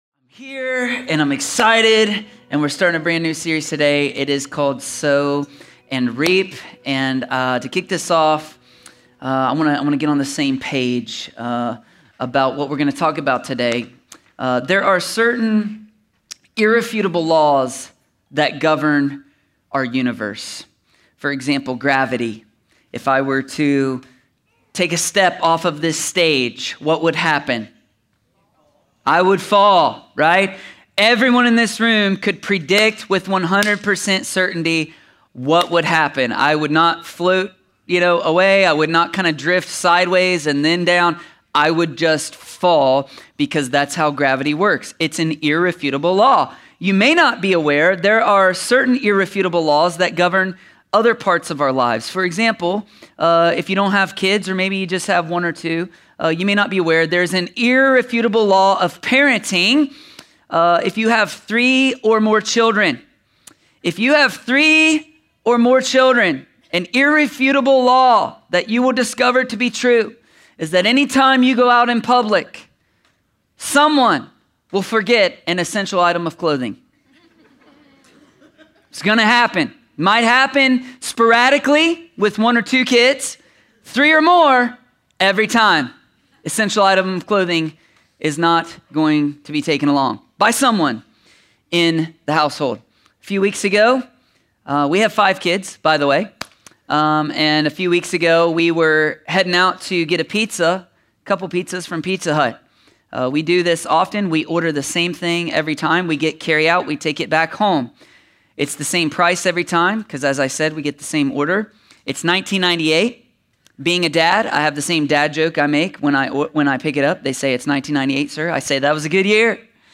A sermon from the series “Sow & Reap.”…